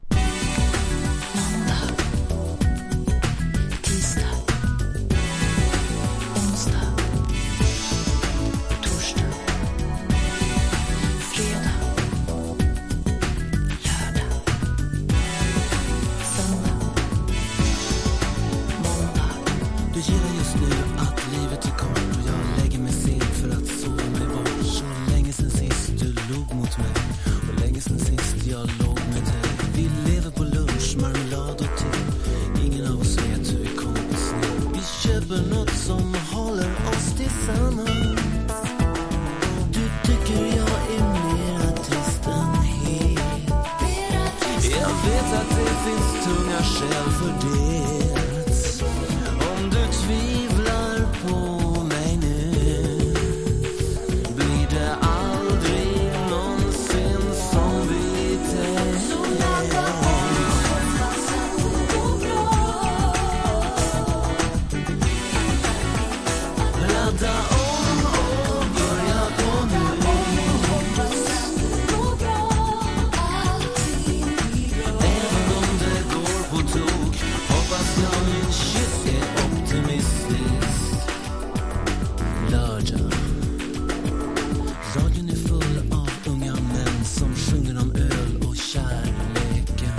ダンサブルなA面！ネオアコ調のB面！！どちらもコーラス・ワークが最高です！！！！